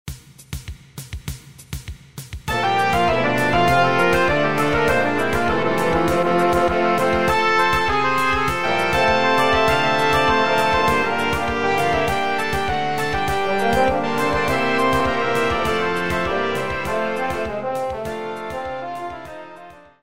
latynoskie , południowoamerykańskie , rozrywkowa , tańce